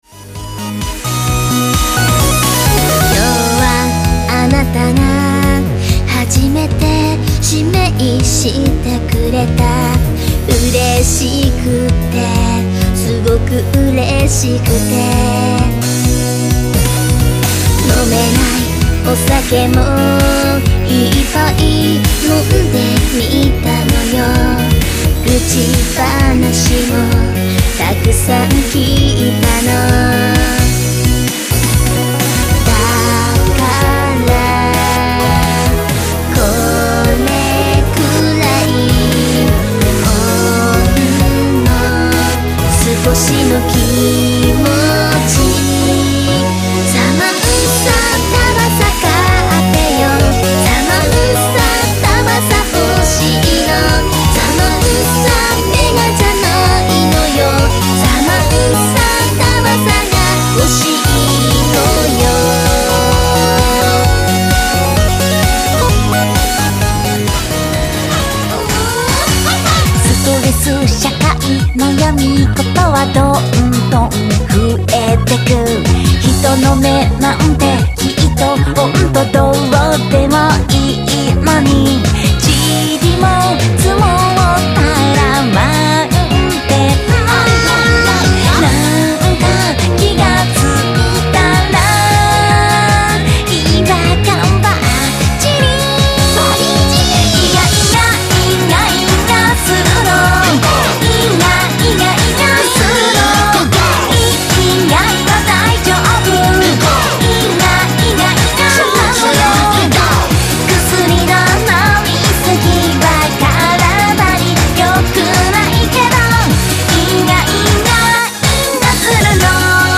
■クロスフェード試聴(tr.1〜tr.3)公開中■
vocal,chorus,voice　：